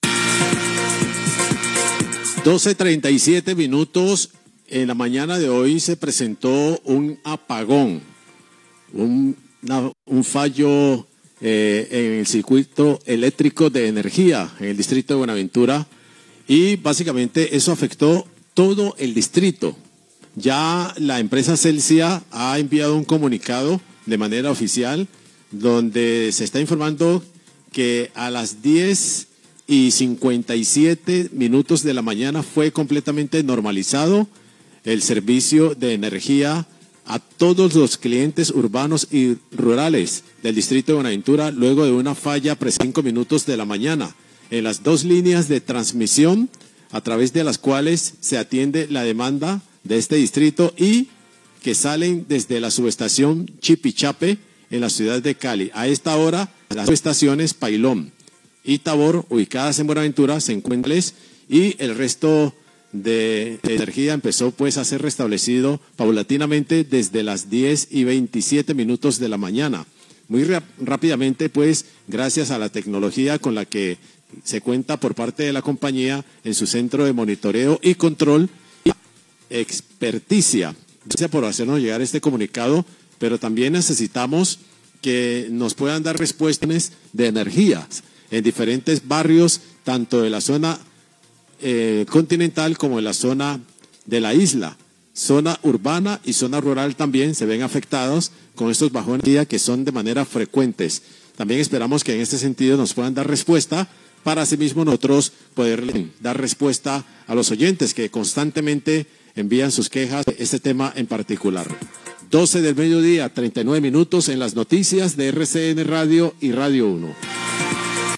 Radio
Locutor lanza interrogante sobre los bajones de nergía que se estan presentado, hace llamado para que la empresa de expelicaciones sobre las causa de esta situación